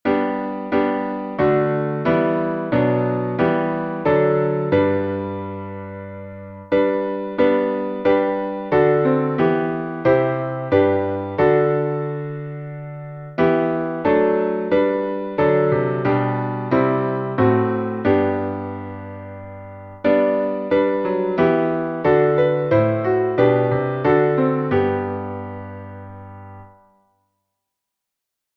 Downloads Áudio Áudio cantado (MP3) Áudio instrumental (MP3) Áudio intrumental (MIDI) Partitura Partitura 4 vozes (PDF) Cifra Cifra (PDF) Cifra editável (Chord Pro) Mais opções Página de downloads
salmo_100B_instrumental.mp3